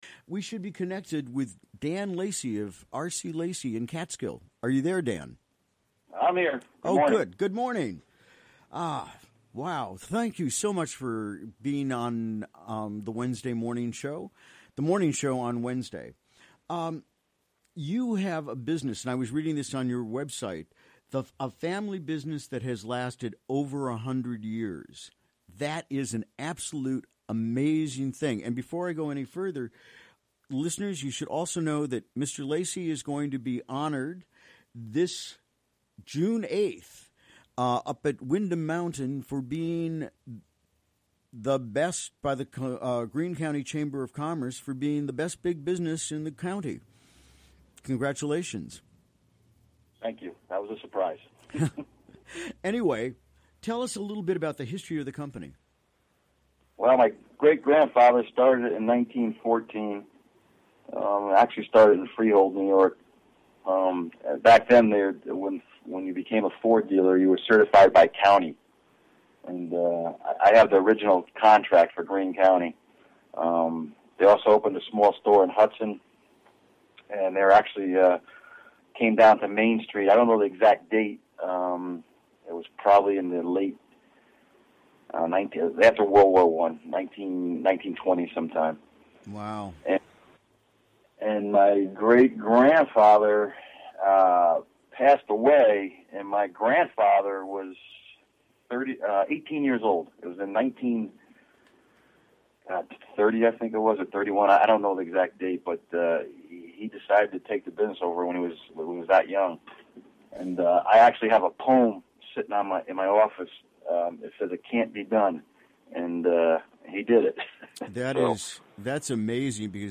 Recorded during the WGXC Morning Show on Wednesday, May 24.